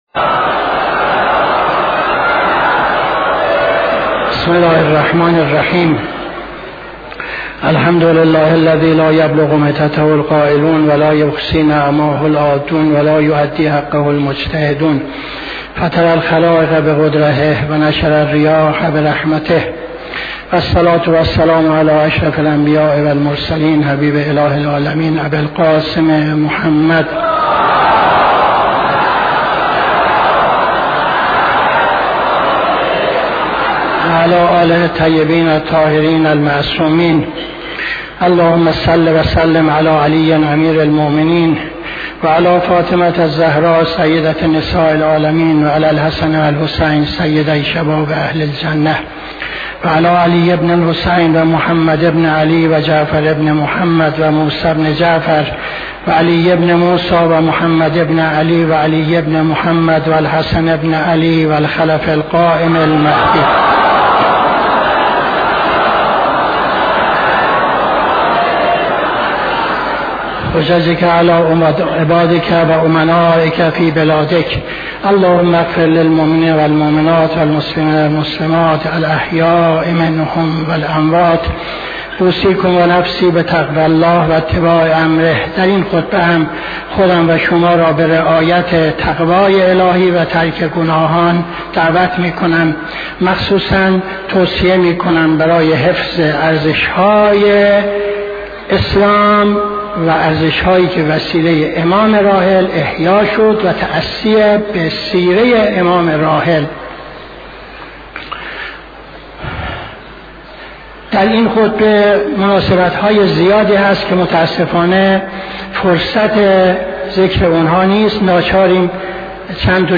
خطبه دوم نماز جمعه 10-02-78